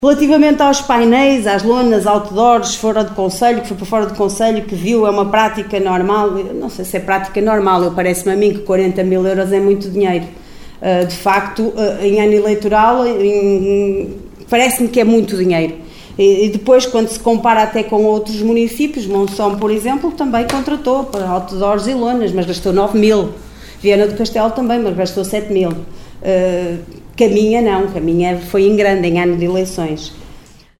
Excertos da última reunião camarária, realizada ontem no Salão Nobre dos Paços do concelho.